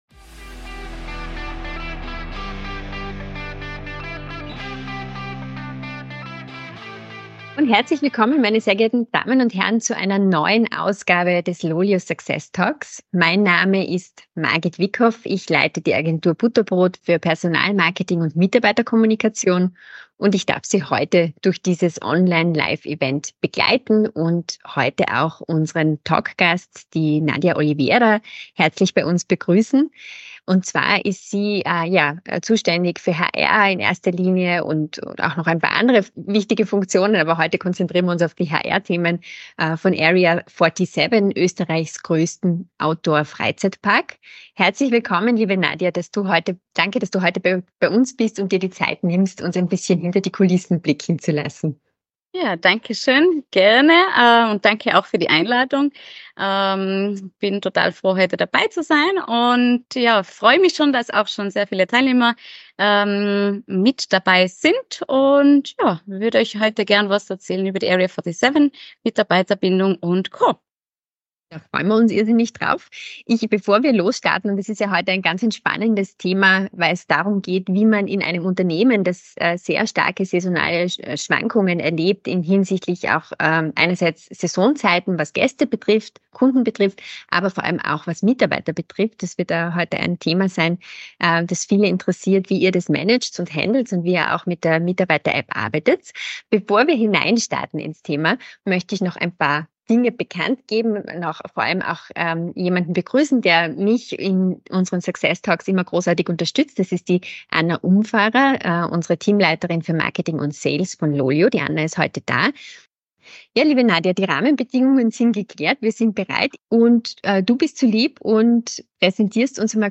Success Talks by LOLYO ist ein Podcast mit ausgewählten Talkgästen und spannenden Themen rund um die unternehmensinterne Kommunikation und Mitarbeiter-Apps.